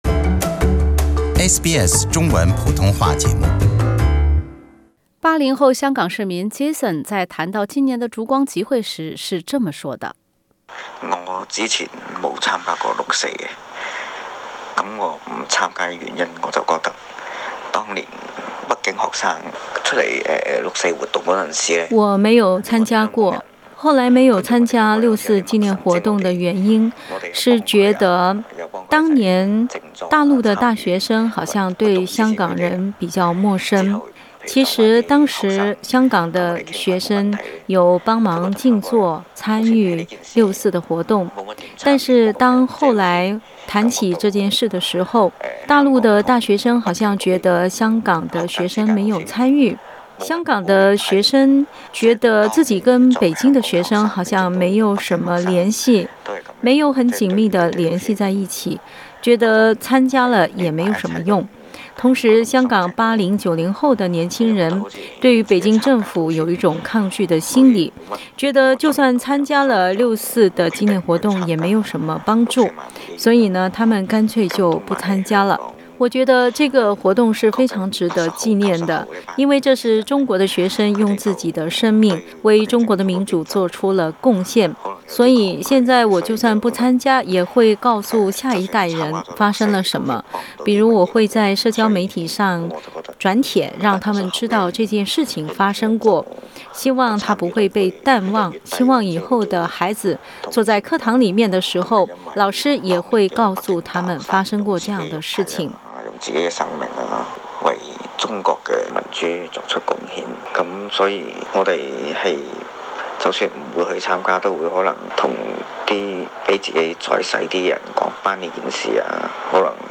今年的六四已然到来，今晚香港维园烛光集会的人数是否会再创新低，也从一定程度上会反映香港大学生和年轻人微妙的思想变化，我们通过电话分别联系了香港本土两位80、90后青年。